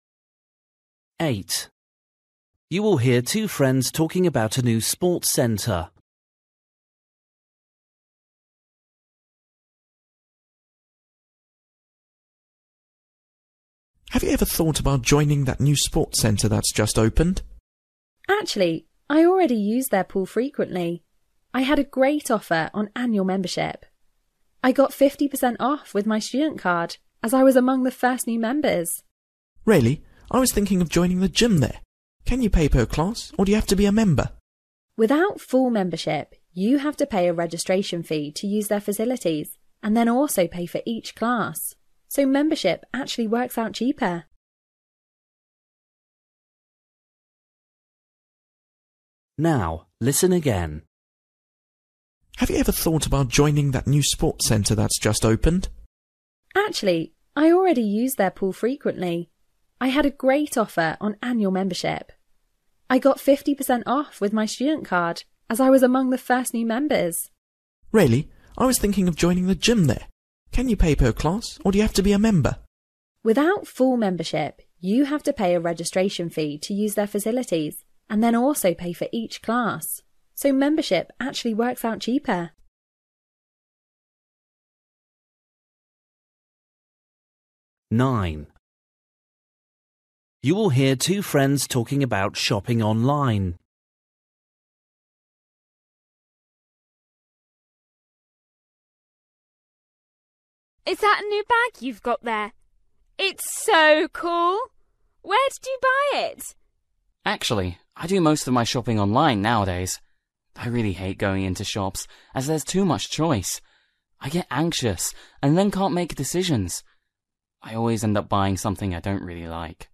Listening: everyday short conversations
8   You will hear two friends talking about a new sports centre. What does the girl say about it?
9   You will hear two friends talking about shopping online. How does the boy feel about it?
10   You will hear a girl telling a friend about a holiday she’s been on. What does the girl say about herself and her cousin?